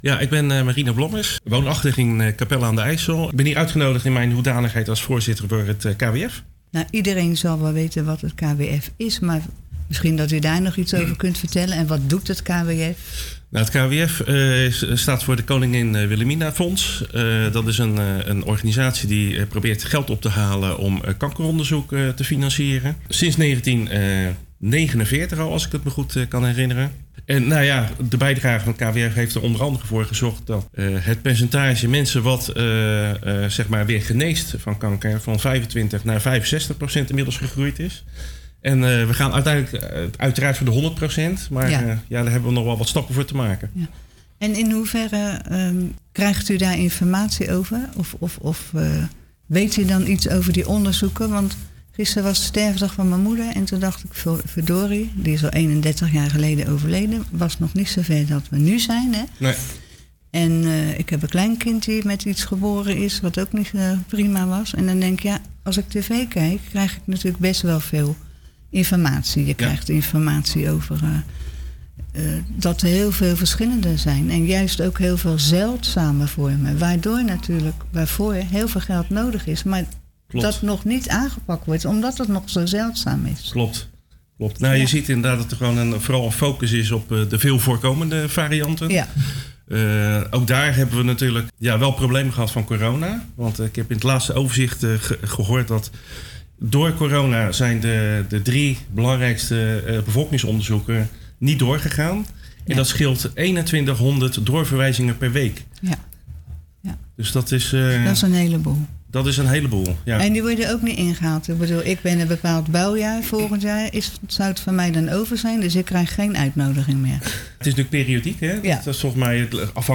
In�de�Kletskoekstudio�van�Radio�Capelle